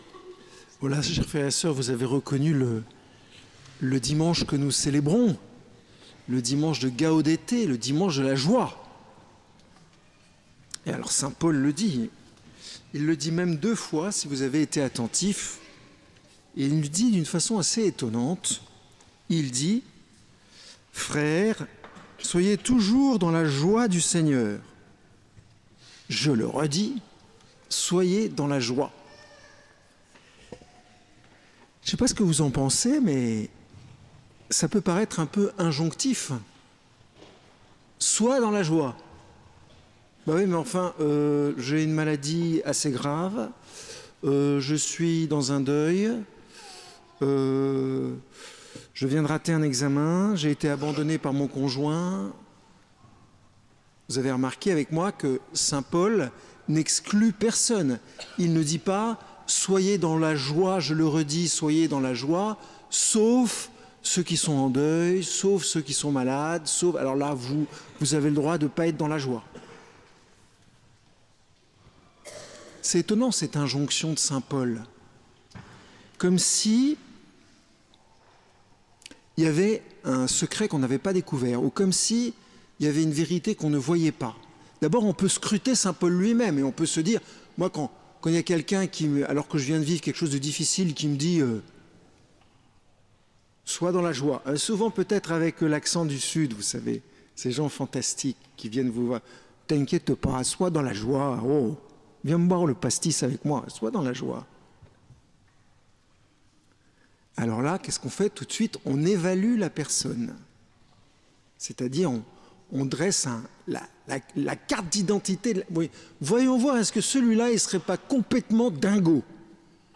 Écoutez ou réécoutez les homélies du dimanche de l'année 2024 à la basilique Notre-Dame des Victoires !